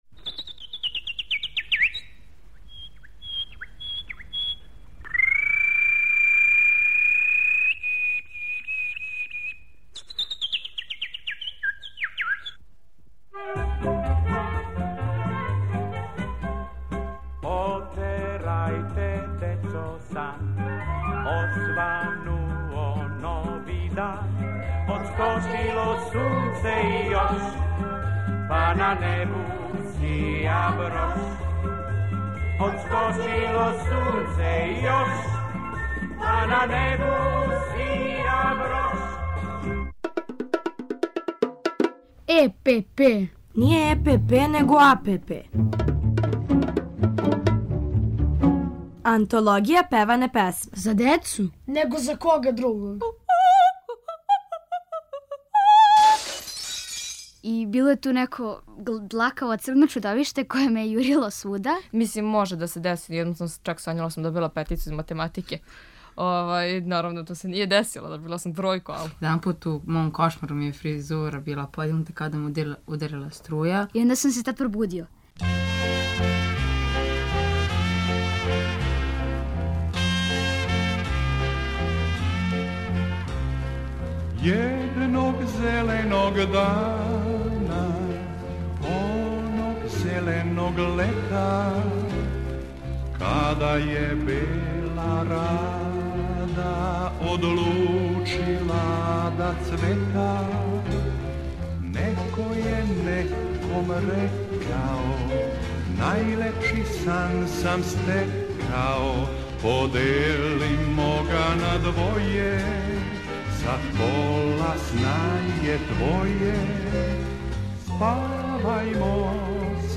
У серијалу Антологија певане песме, још једном певамо и размишљамо о сновима, уз Колибре и Дечју драмску групу Радио Београда.